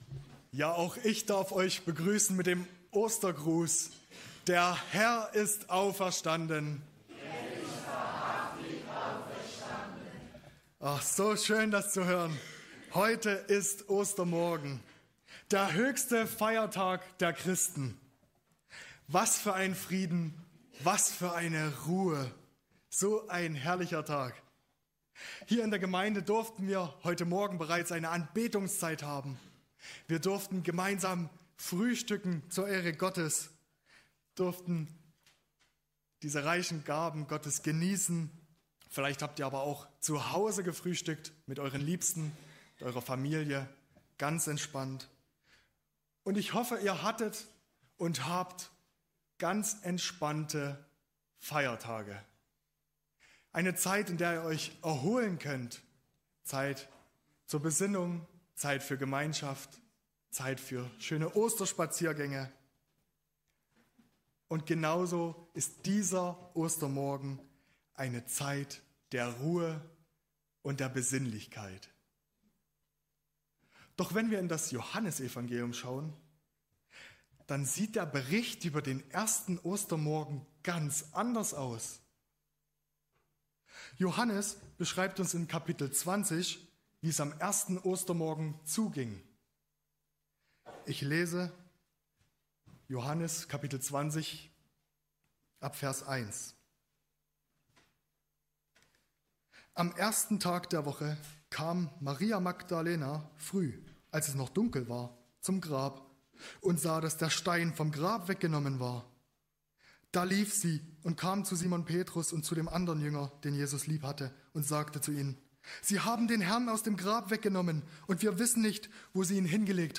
Kategorie: Oster-Gottesdienst